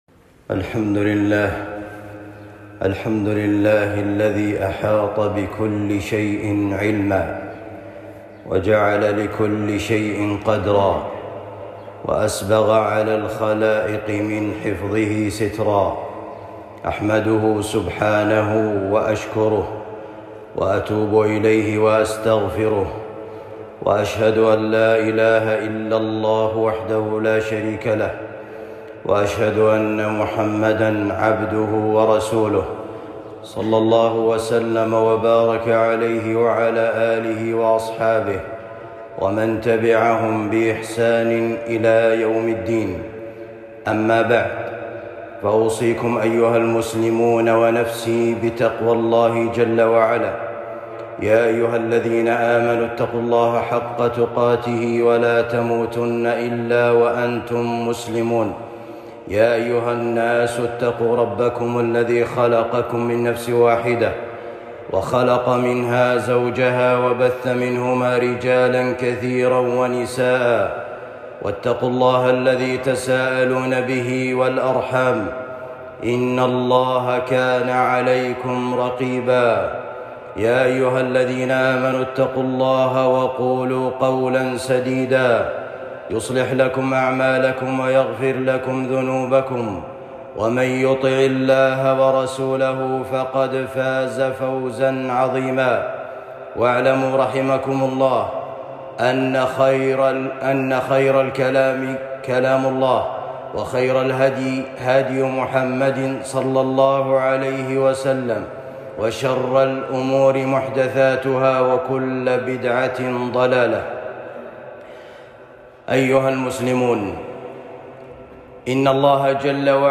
تحصين الآبار المهجورة والحث على صيانتها خطبة جمعة